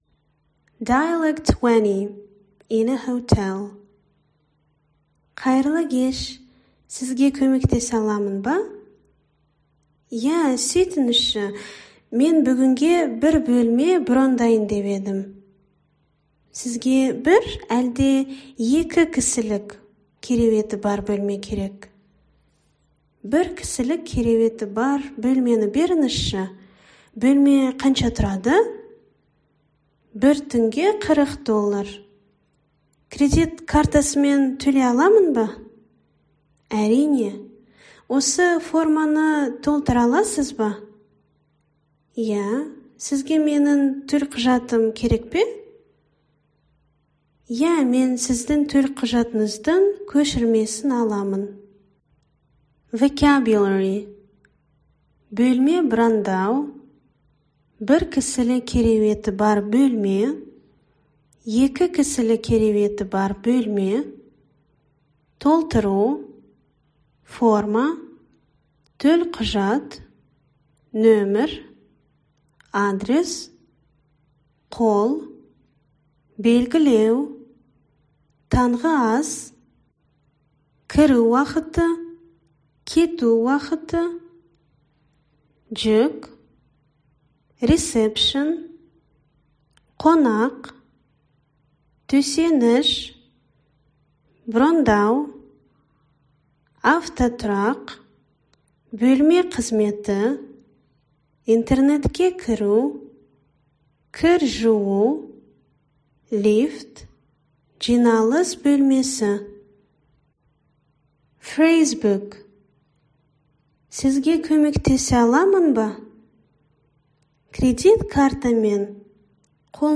Native Speaker Audio
All dialogues, words, and phrases are voiced by native Kazakh speakers at a comfortable speed, allowing you to practice listening and pronunciation.
Conversation 20